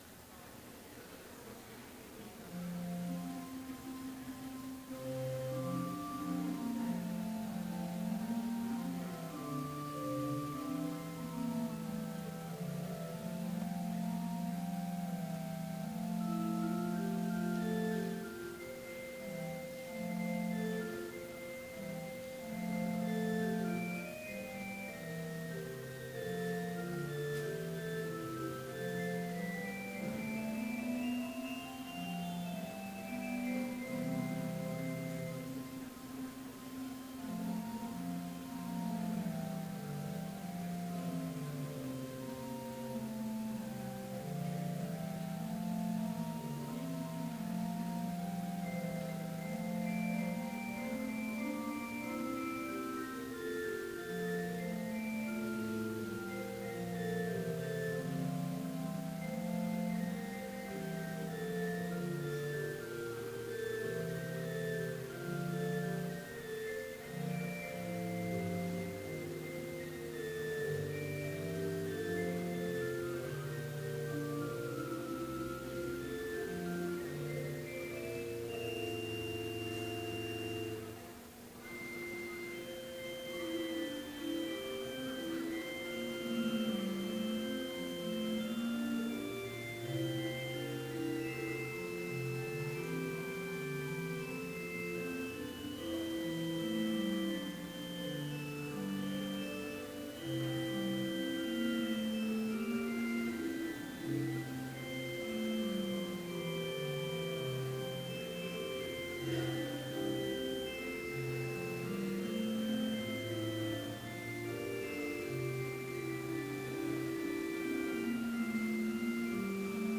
Complete service audio for Chapel - December 16, 2015